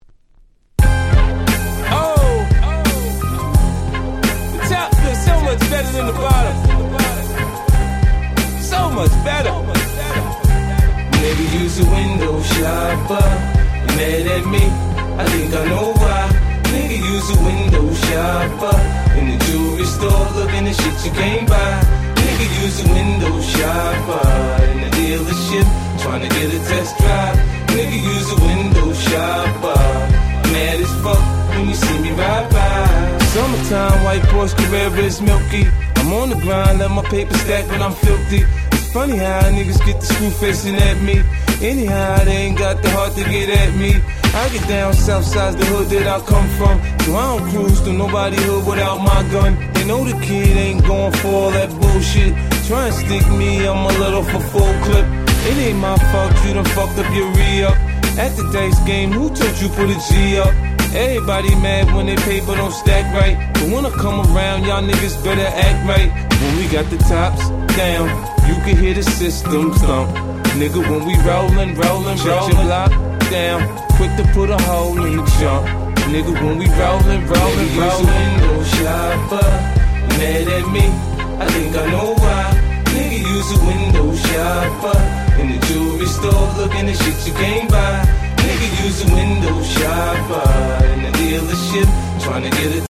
05' Smash Hit Hip Hop !!